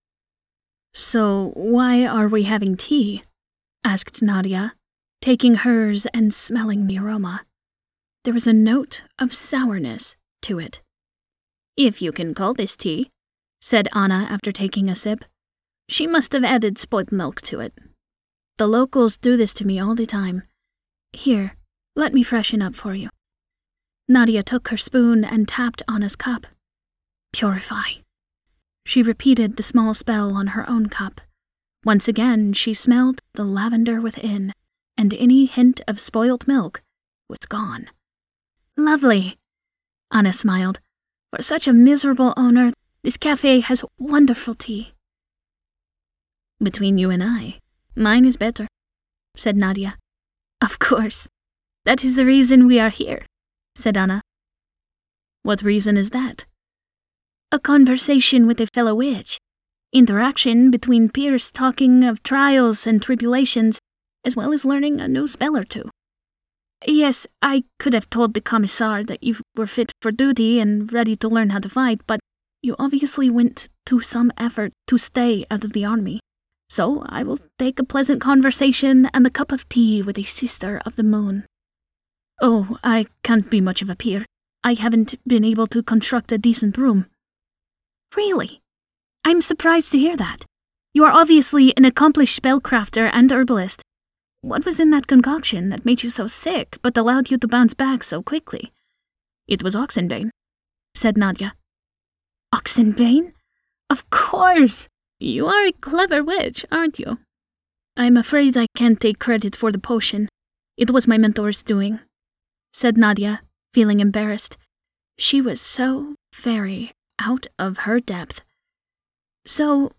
Main voice: nurturing, playful and compassionate.
Audiobooks
Russian-American F Accent
0326Russian_lite_accent.mp3